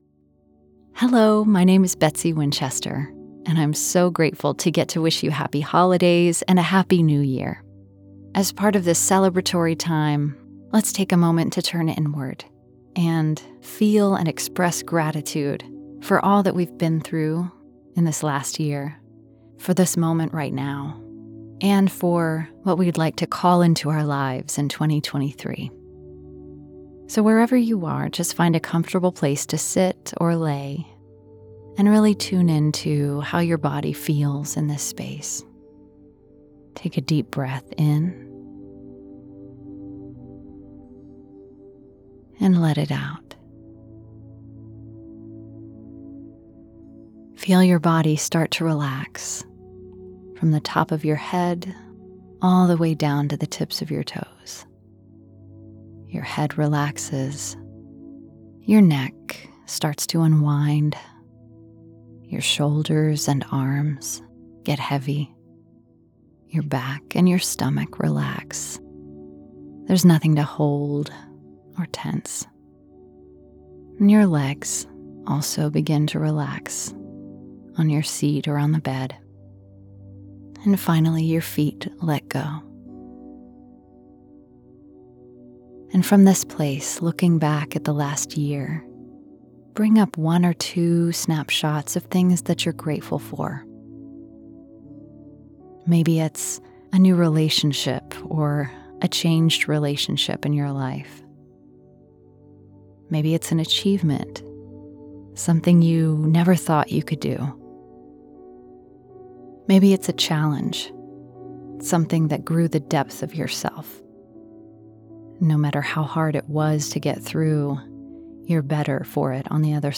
standard us
meditation